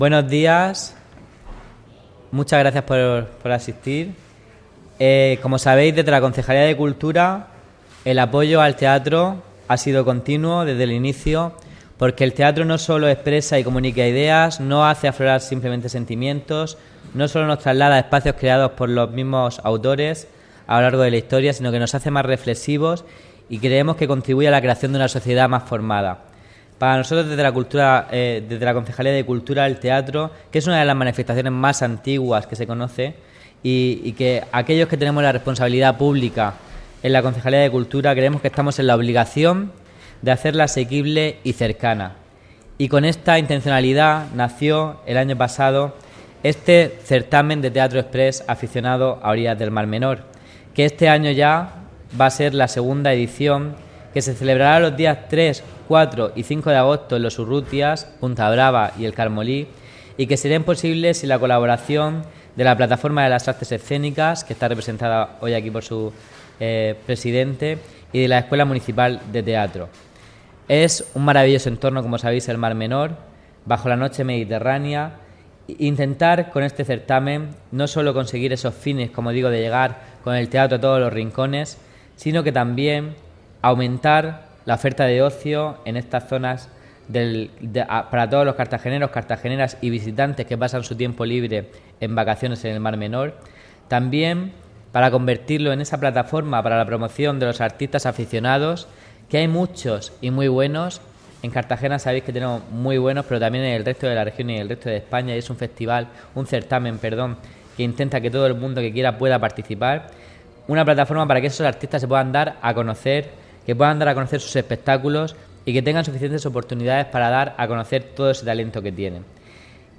Rueda de prensa de presentación del II Certamen de Teatro Espress 'A orillas del Mar Menor' (MP3 - 6,90 MB) La II edición del Certamen de Teatro Express 'A orillas del Mar Menor' busca proyectarse a nivel nacional